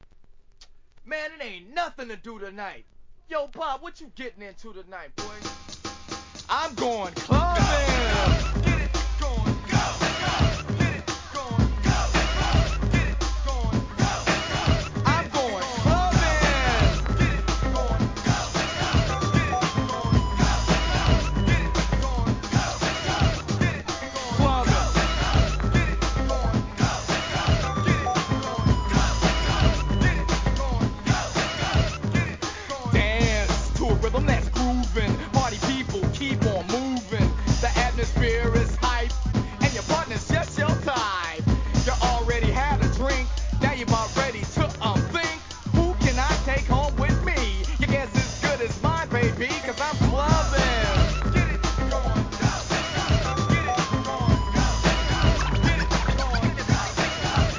HIP HOP/R&B
大ネタ多数の人気ミドル!!